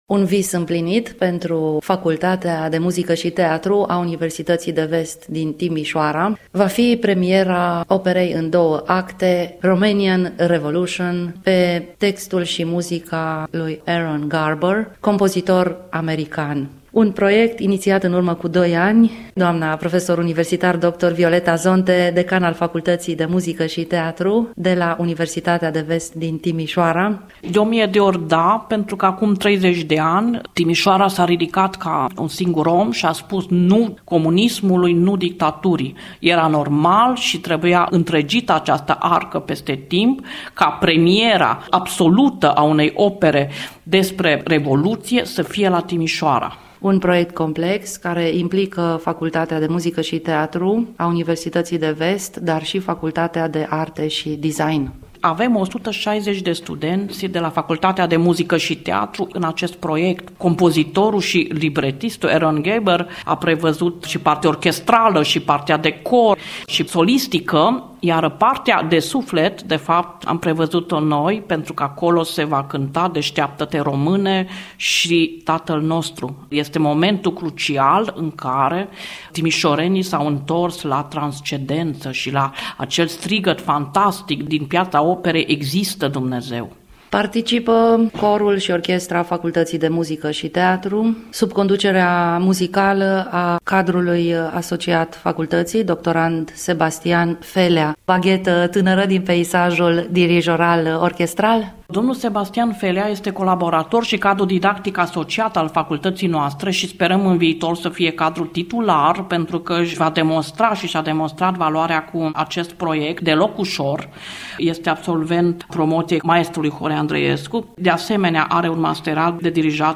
Reportaj-inainte-de-repetitia-generala.mp3